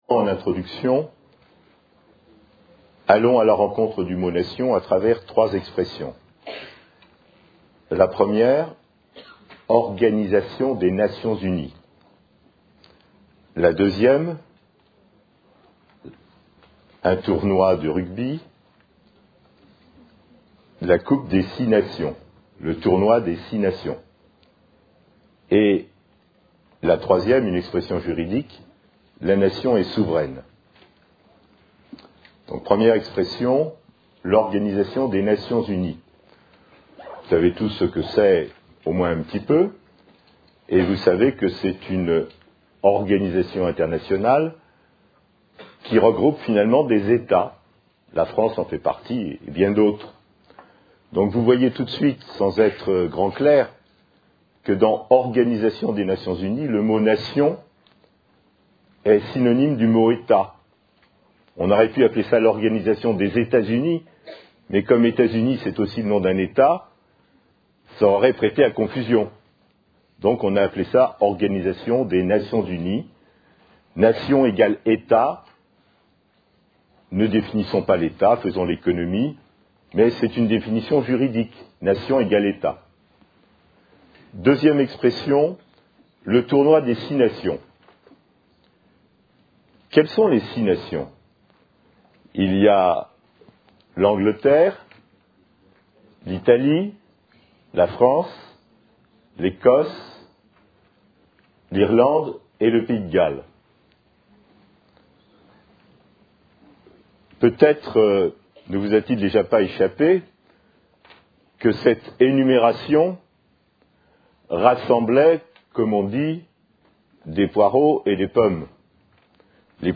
Une conférence de l'UTLS au Lycée Qu'est ce qu'une nation